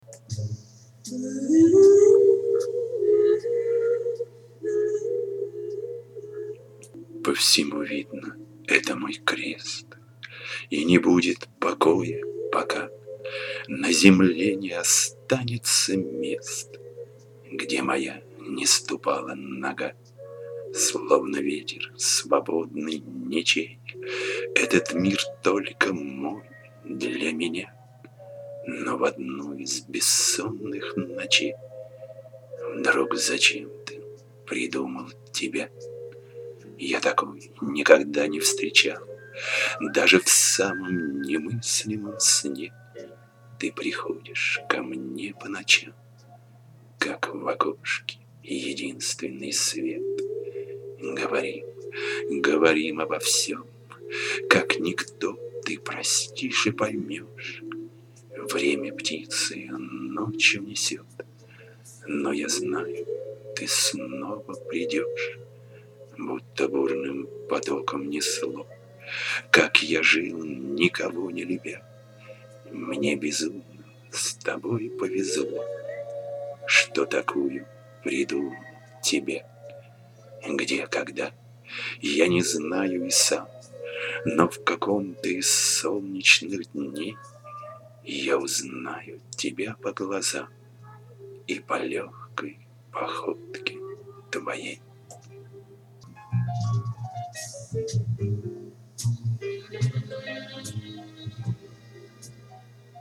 Хорошая ваша декламация,нравится.